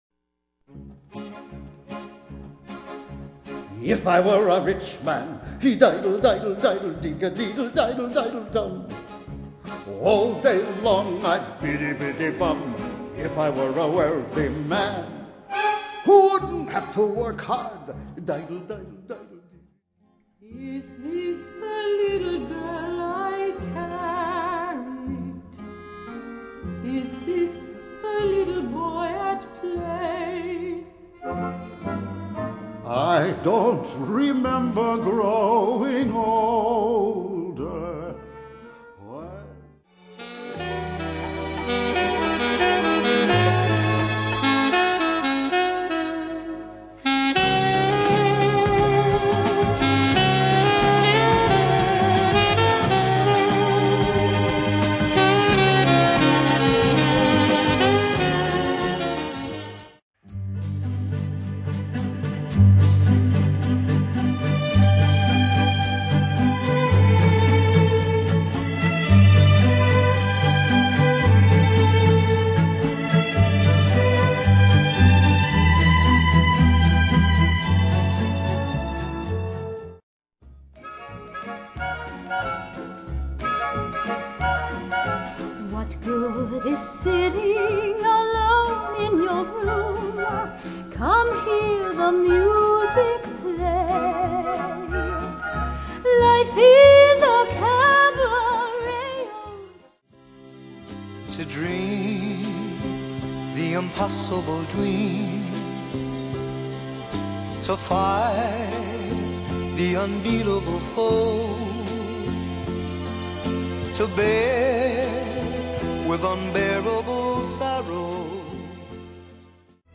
Vocals
Broadway & Film